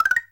powerup_pickup.wav